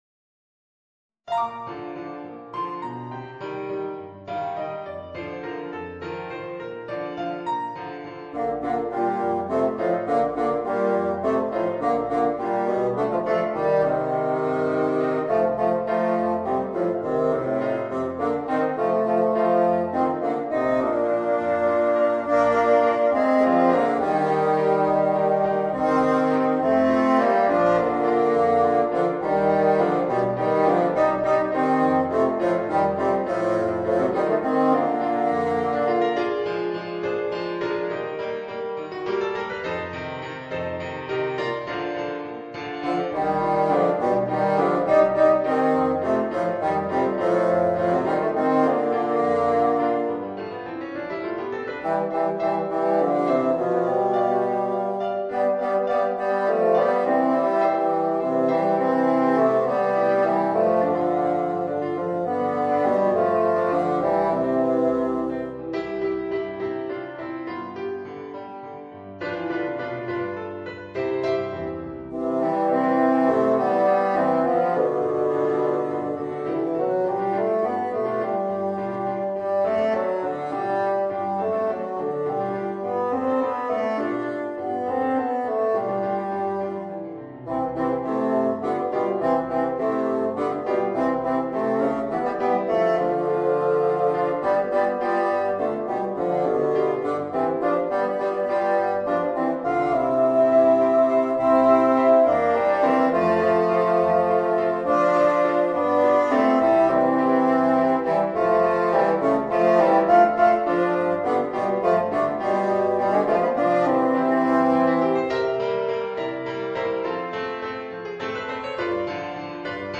Voicing: 2 Bassoons and Piano